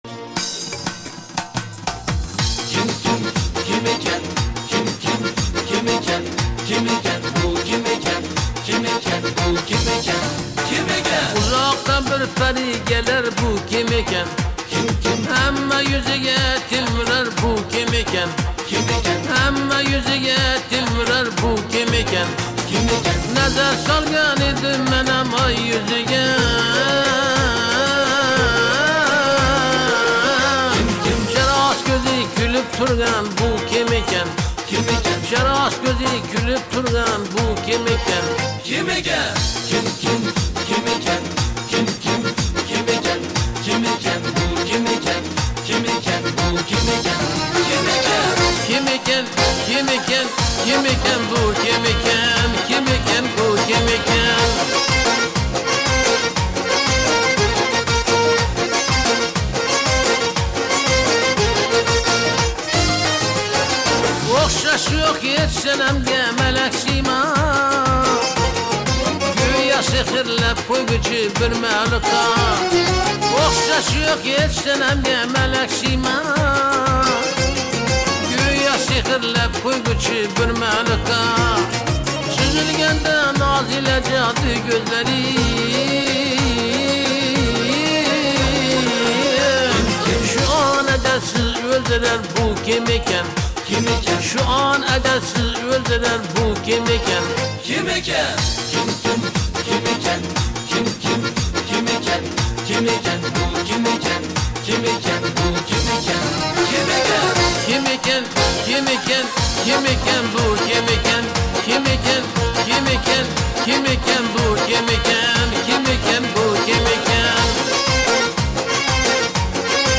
Ўзбекистон мусиқаси